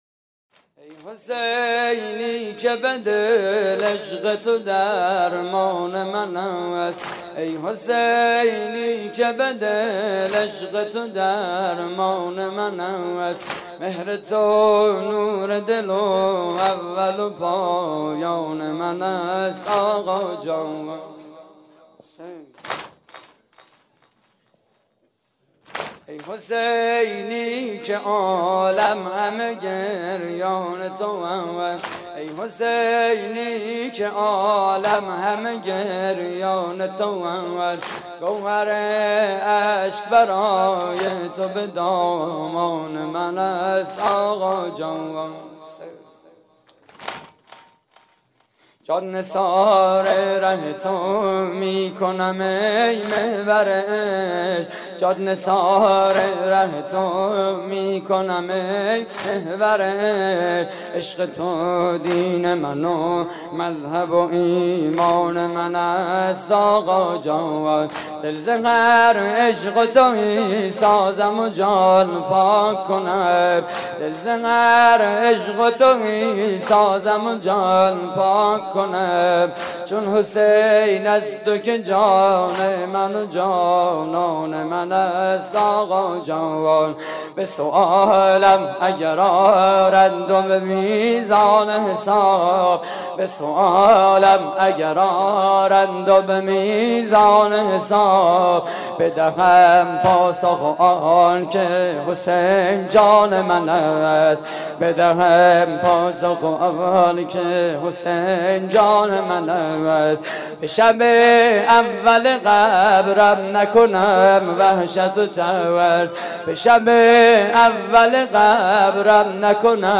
واحد: ای حسینی که به دل عشق تو درمان من است
مراسم عزاداری شب عاشورای حسینی (محرم 1433)